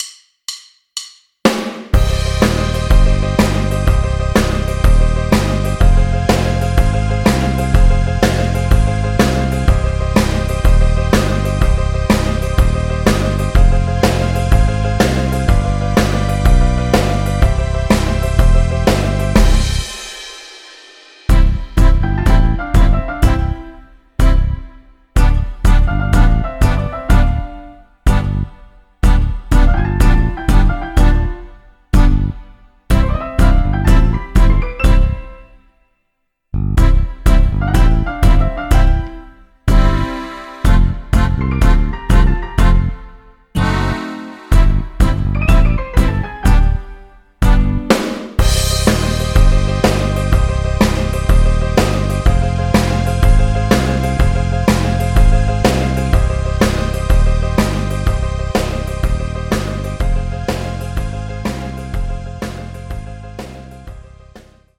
karaoke, strumentale